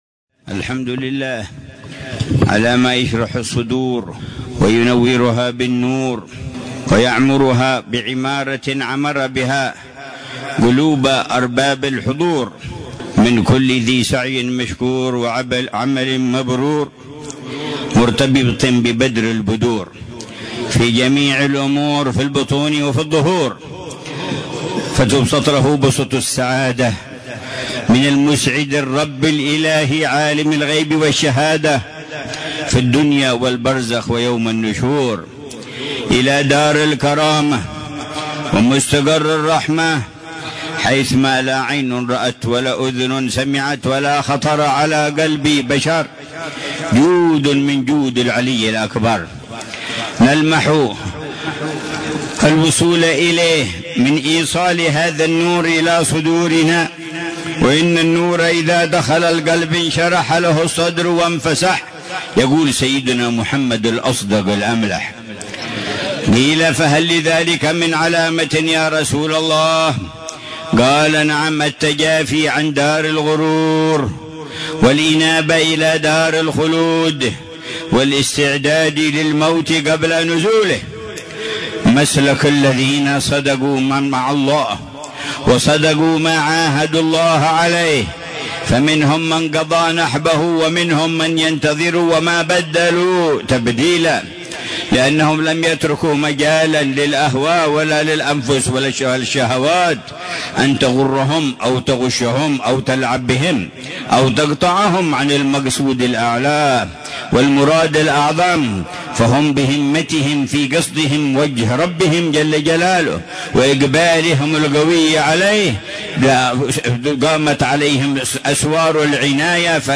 مذاكرة العلامة الحبيب عمر بن محمد بن حفيظ في ساحة كثيب الشيخ أبي بكر بن سالم، بمنطقة عينات، ليلة الإثنين 27 ربيع الأول 1446هـ بعنوان: